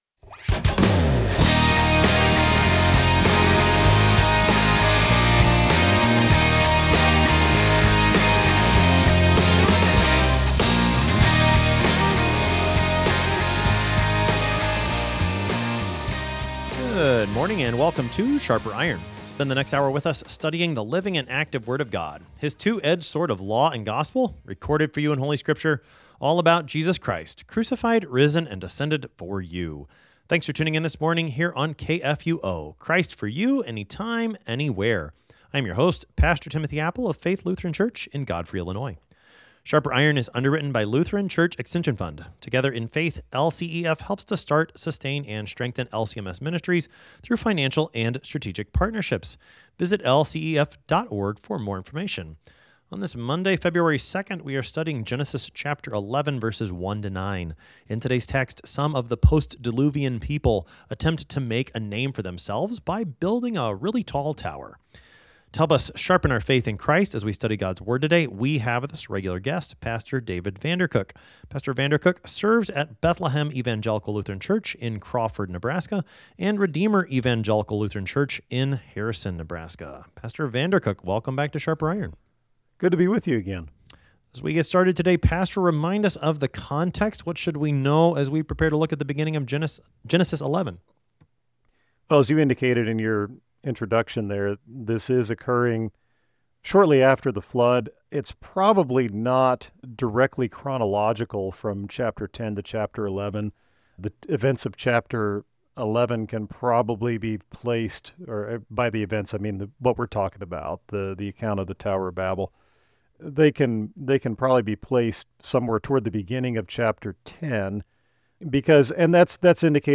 Two pastors engage with God's Word to sharpen not only their own faith and knowledge, but the faith and knowledge of all who listen.